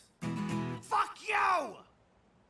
suction-cup-man.mp3